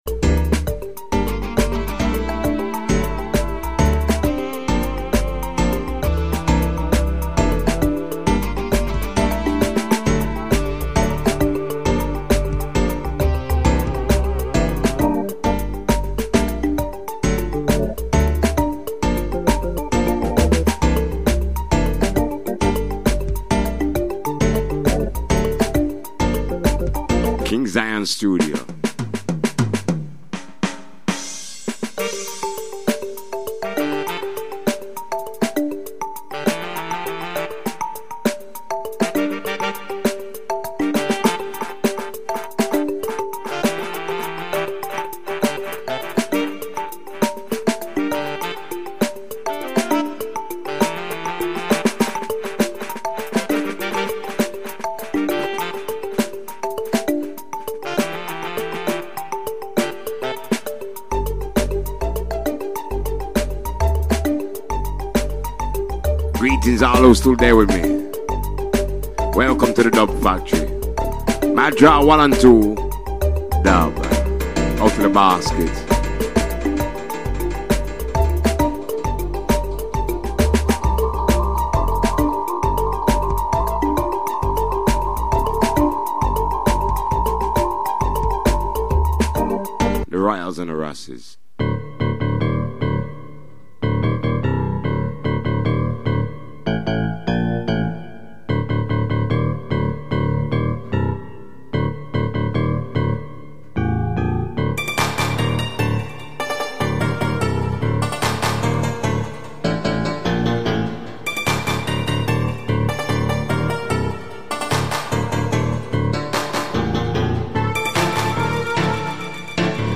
ROOTS & CULTURE / DUB / STEPPAS VIBES